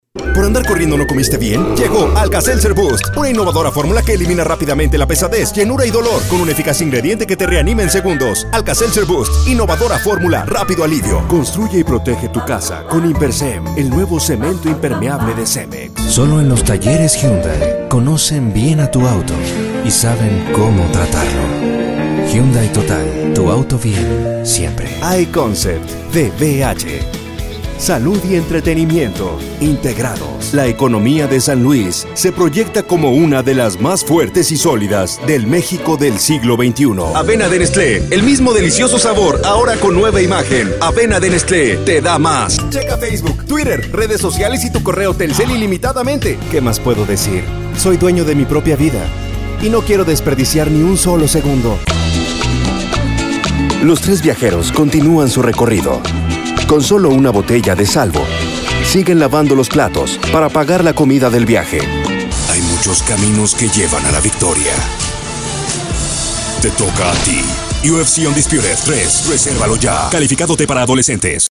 Spanisch (Lateinamerika)
Über meine Stimme Stimmalter: 25 bis 65 Jahre Stil: warm, elegant, natürlich, konversationell, Verkäufer, Unternehmen...
Vertrauenswürdig
Warm
Freundlich